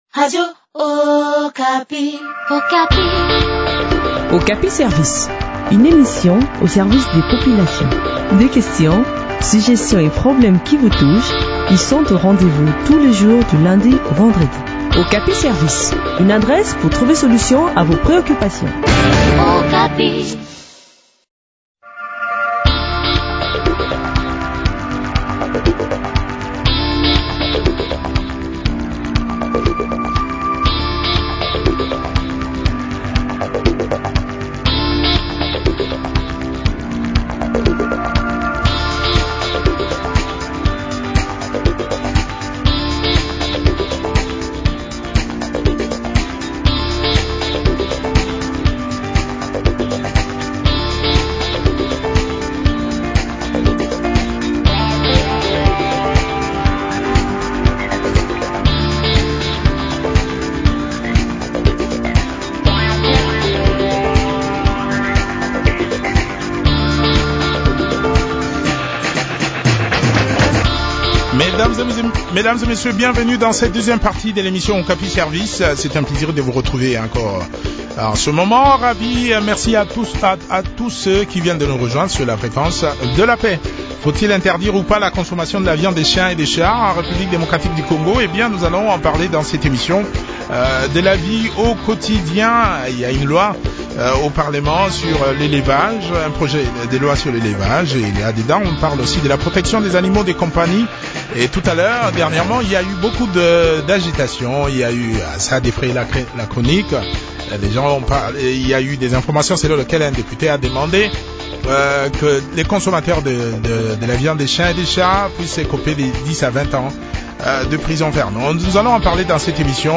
médecin vétérinaire et épidémiologiste a aussi pris part à cet entretien.